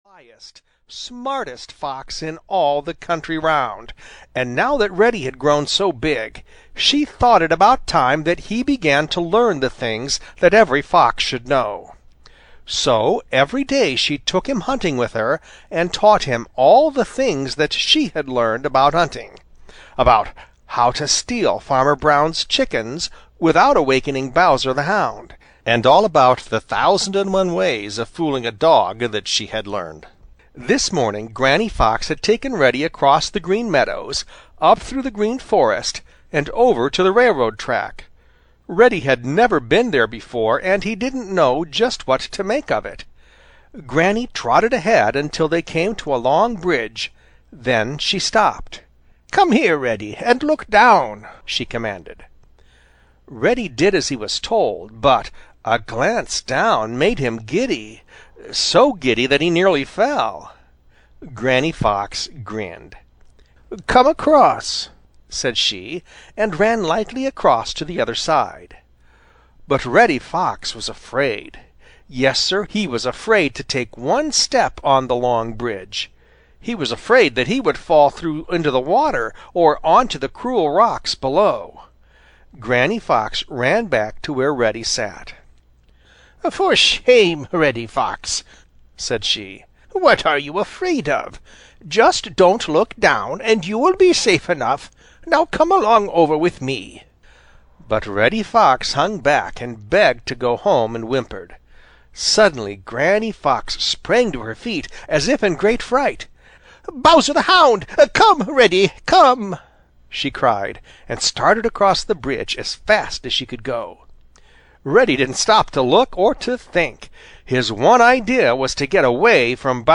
The Adventures of Reddy Fox (EN) audiokniha
Ukázka z knihy